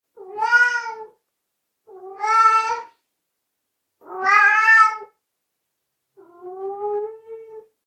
gato4a
cat4a.mp3